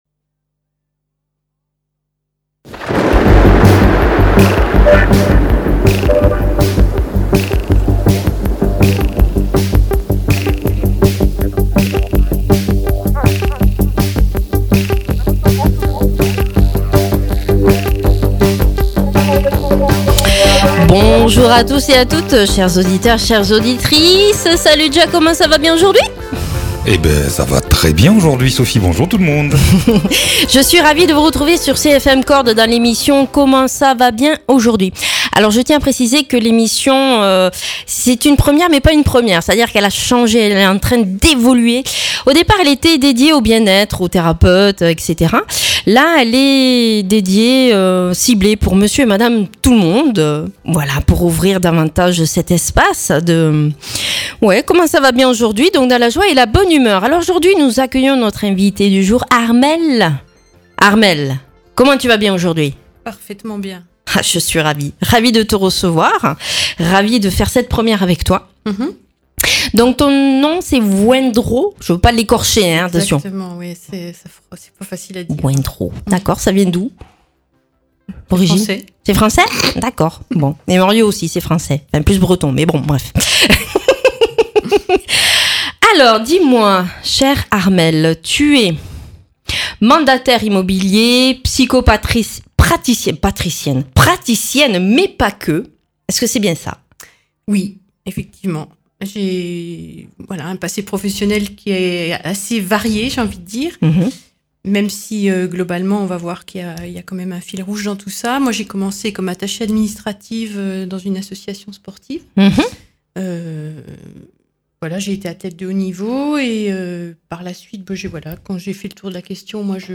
judokate et psychopraticienne.